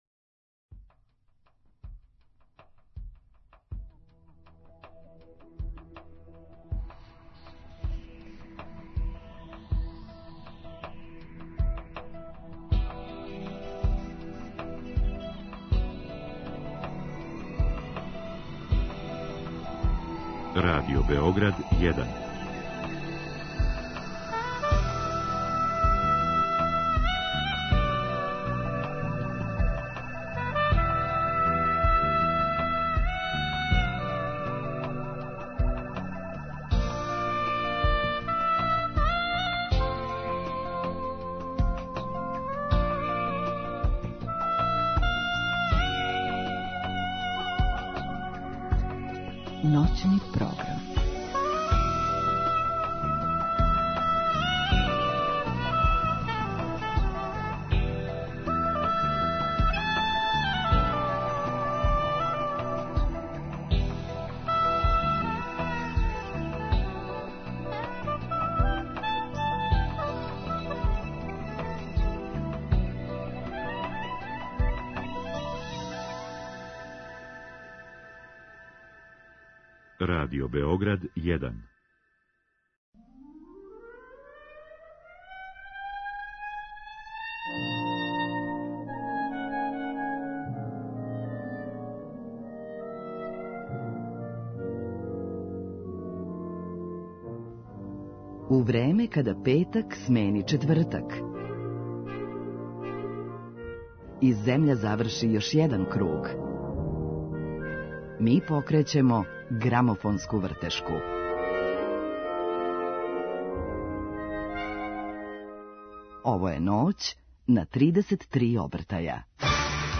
виолиниста
У Ноћном програму имаћемо прилике да премијерно чујемо нове композиције и да сазнамо како су настајале. Откриће нам и ко је све дао свој печат његовој ауторској музици.